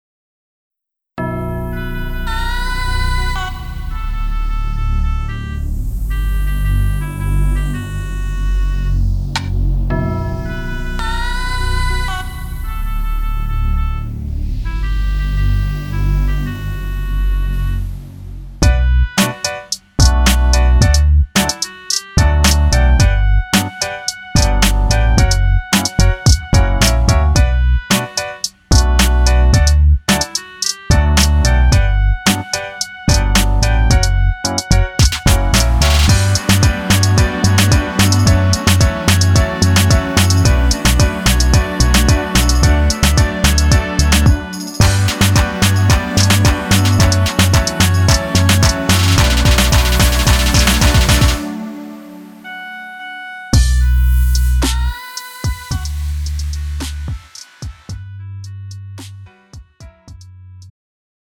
가요
Pro MR